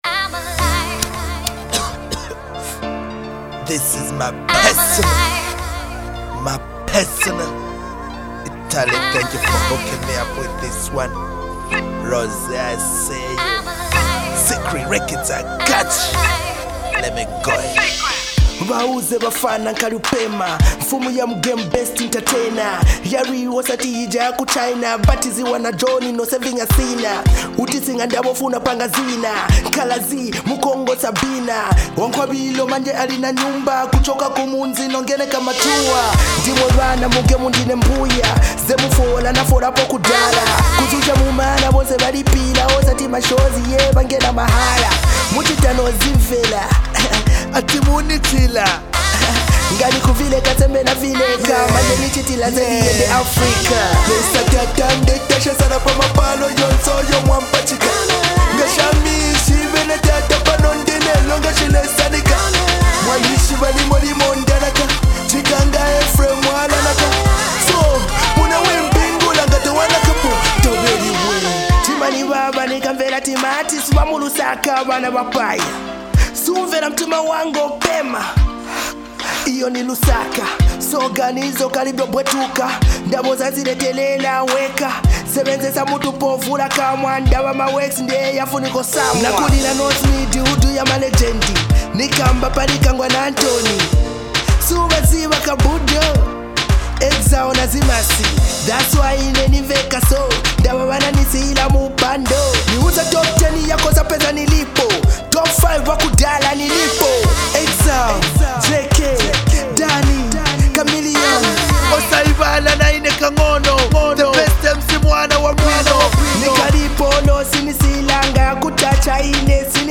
The track radiates energy and defiance
dynamic production